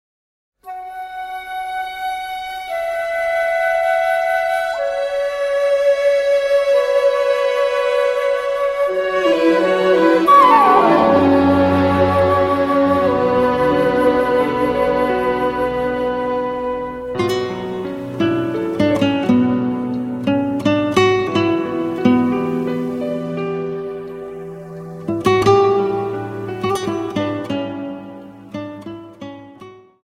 Dance: Waltz